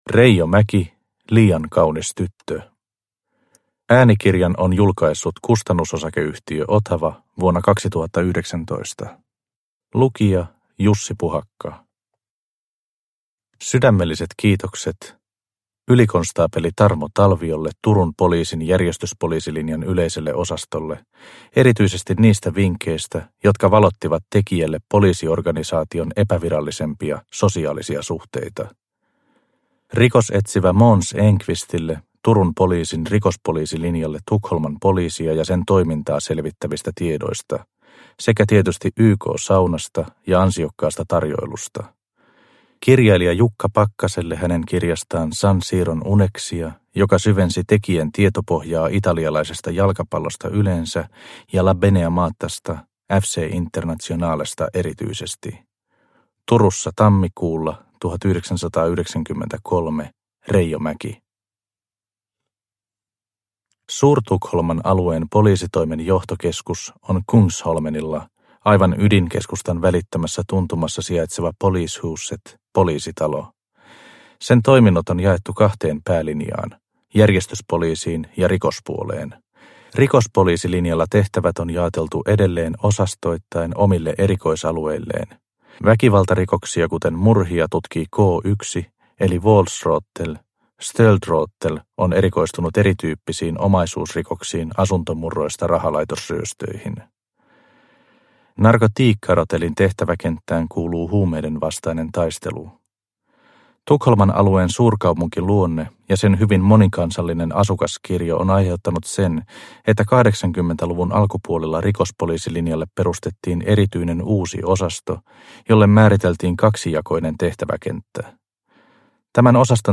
Liian kaunis tyttö – Ljudbok – Laddas ner